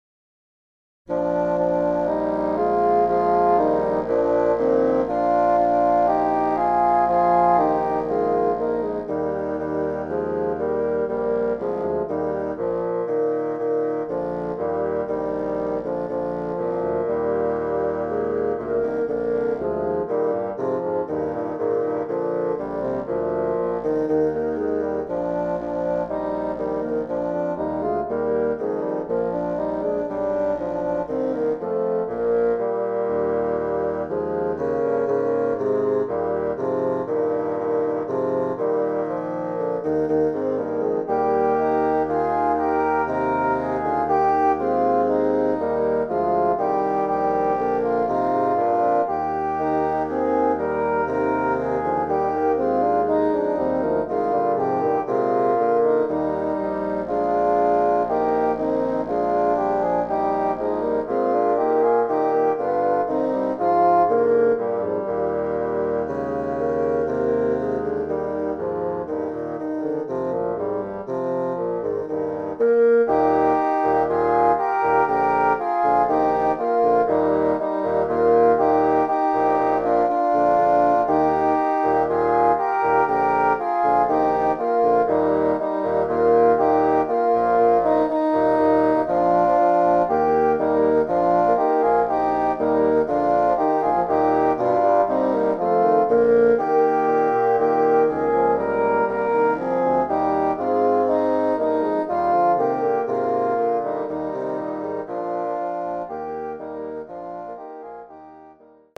Voicing: Bassoon Quartet